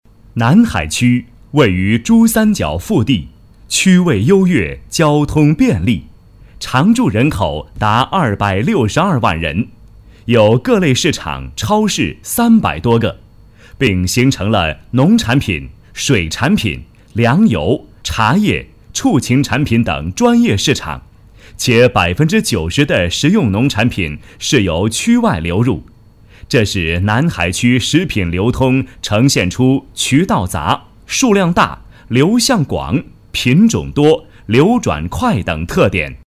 C男65号
【课件】干脆亲切 南海区介绍PPT 干音
【课件】干脆亲切 南海区介绍PPT 干音.mp3